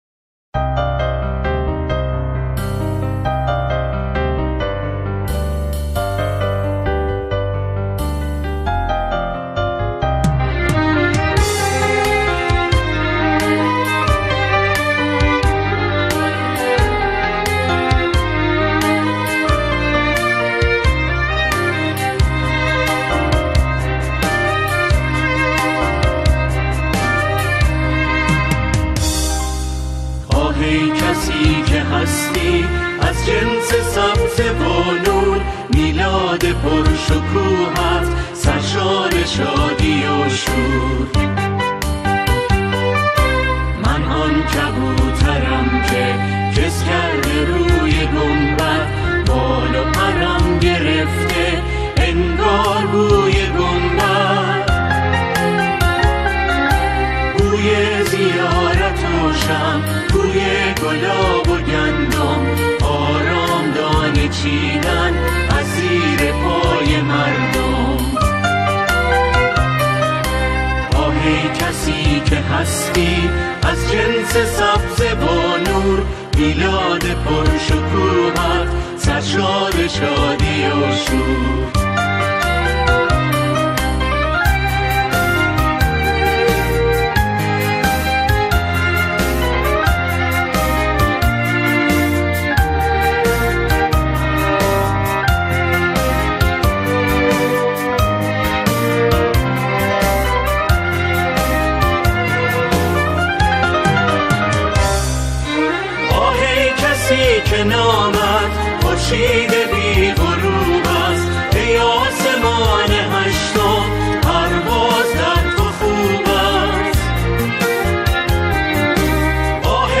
همخوانی
با صدای اعضای گروه کر